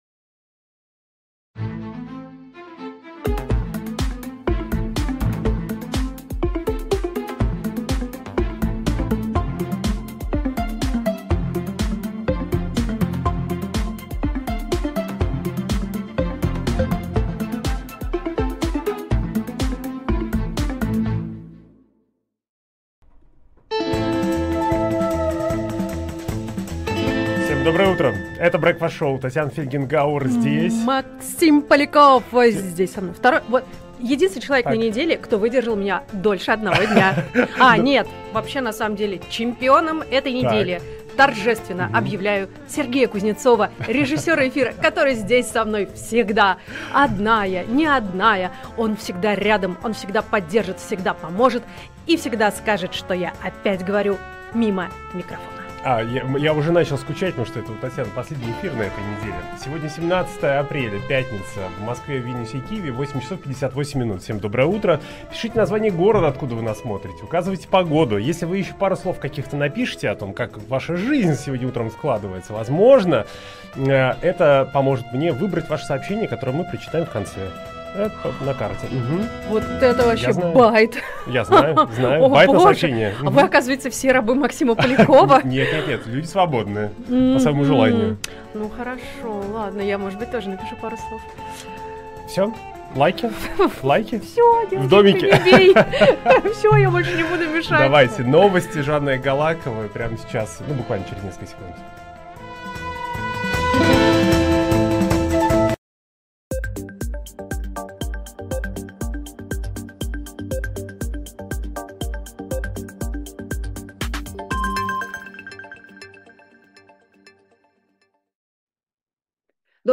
Утренний эфир с гостями